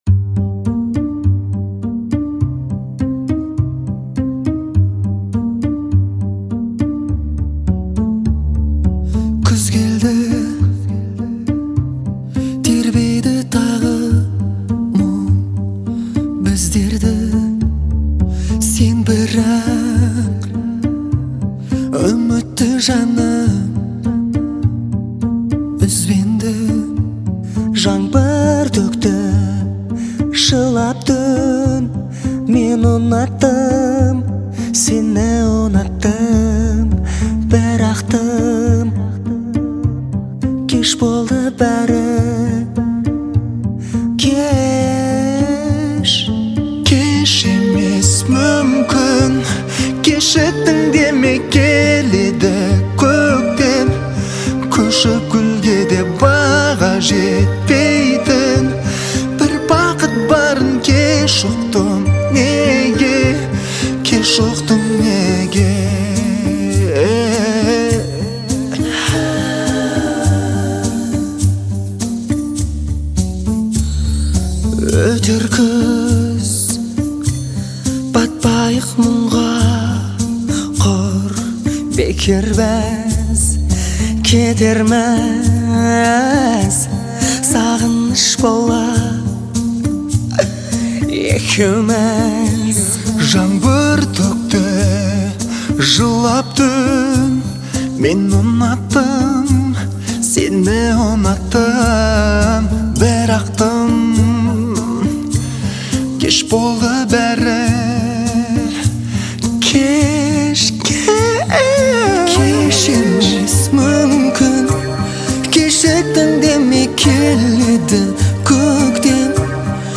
исполняемая в жанре поп.